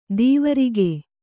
♪ dīvaige